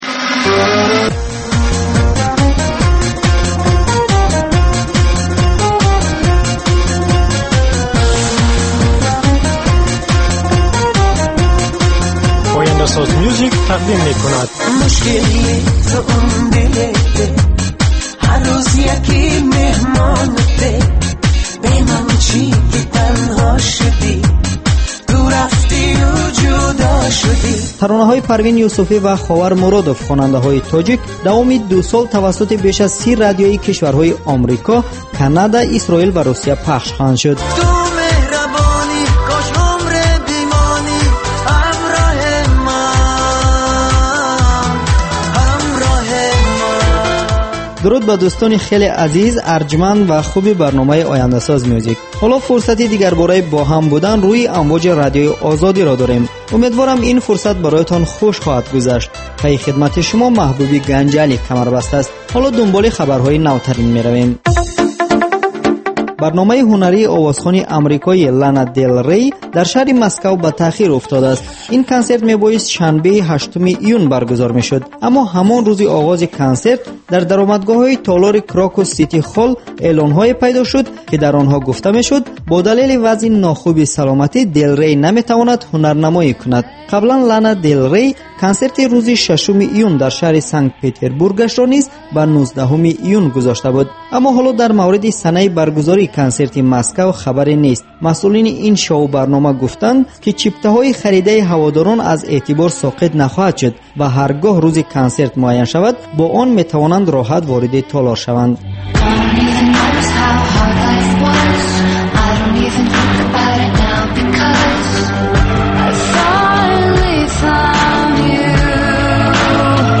Навгониҳои мусиқӣ, беҳтарин оҳангҳо, гуфтугӯ бо оҳангсозон, овозхонон ва бинандагон, гузориш аз консертҳо ва маҳфилҳои ҳунарӣ.